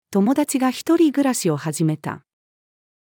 友達が一人暮らしを始めた。-female.mp3